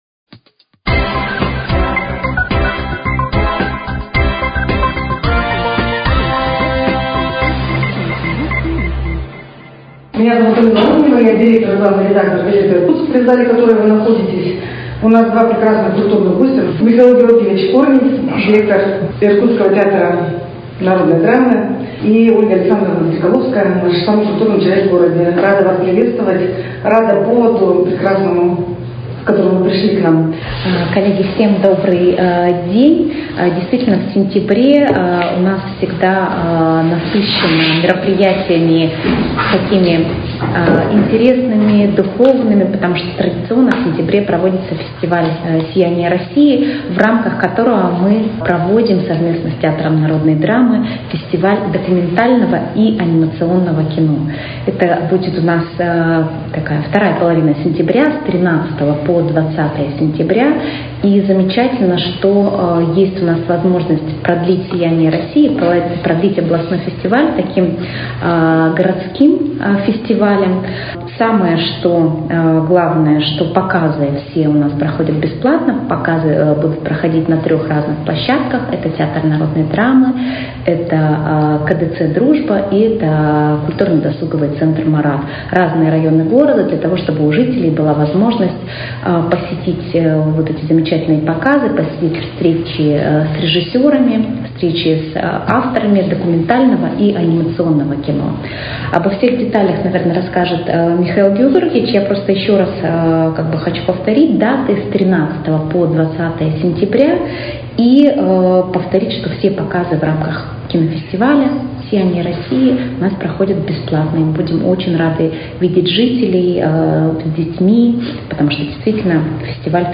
В столице региона состоится II Всероссийский фестиваль документального и анимационного кино. Он пройдет с 13 по 20 сентября в рамках Дней русской духовности и культуры «Сияние России». Подробнее о мероприятиях рассказали на пресс-конференции, прошедшей в пресс-зале газеты «Иркутск»